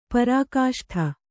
♪ parākāṣṭha